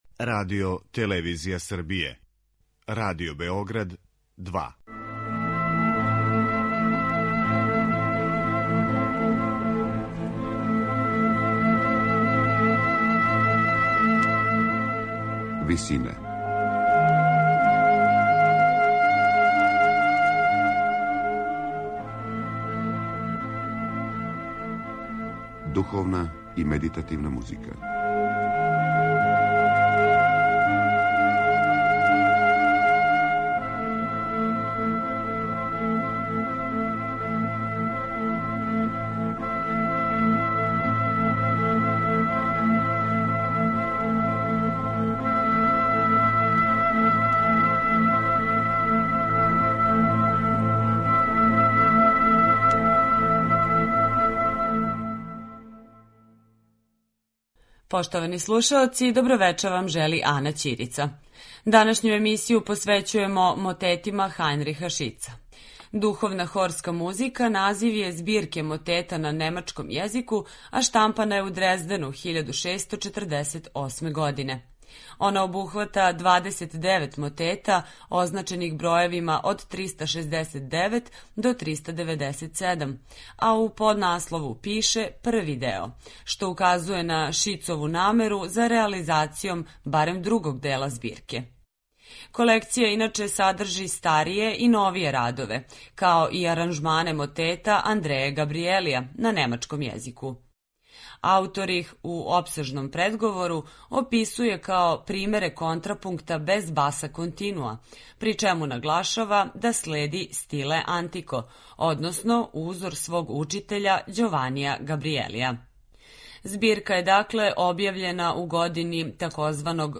Мотети